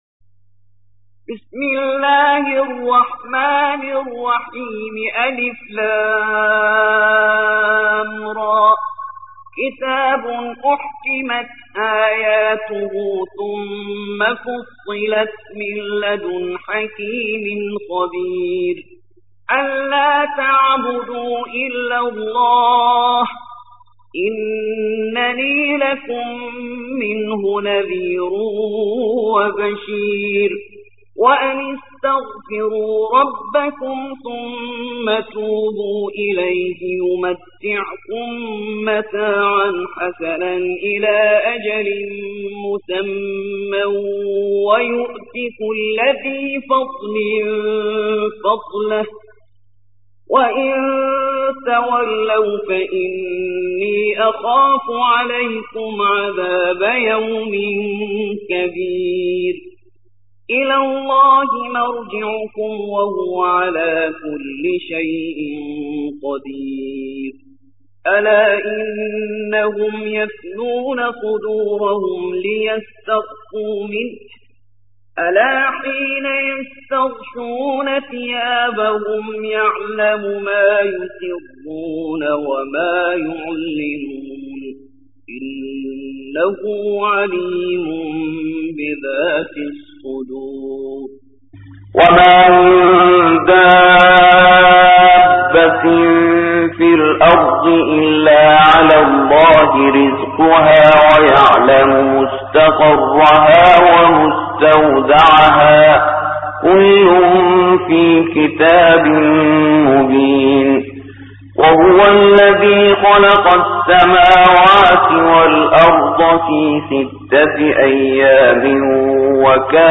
11. سورة هود / القارئ